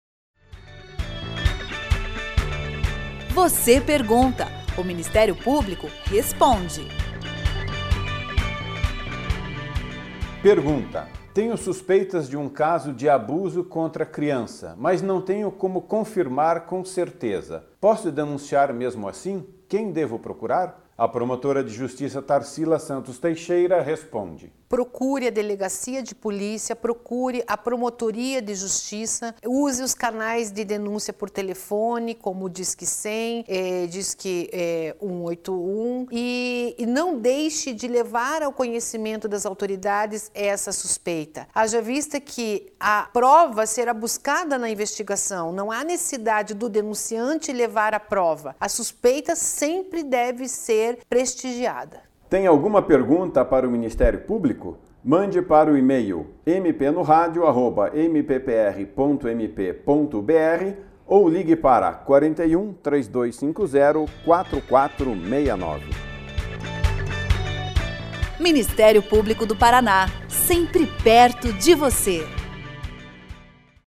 Clique no play para ouvir resposta da promotora de Justiça, Tarcila Santos Teixeira:
Em áudios curtos, de até um minuto, procuradores e promotores de Justiça esclarecem dúvidas da população sobre questões relacionadas às áreas de atuação do Ministério Público.